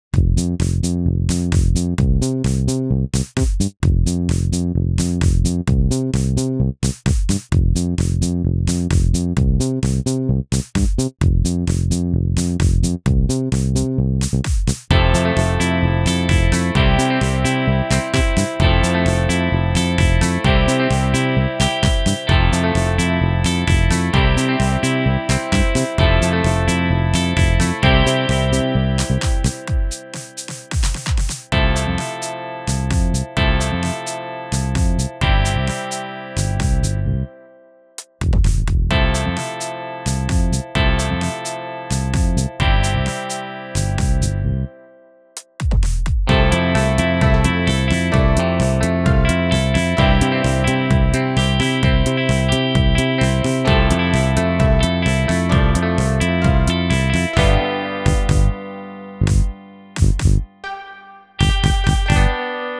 だからね、今はiPadで演奏を作っている所です。
君はデモっぽいのが好きって言ってたから、今は始まりの過程で自分のイメージともほど遠くてまじしょぼいけど昨日作った1番のサビ前までのやつ貼ってみるね。デモっていうか、ただの演奏やけど。